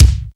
HEAVY KICK.WAV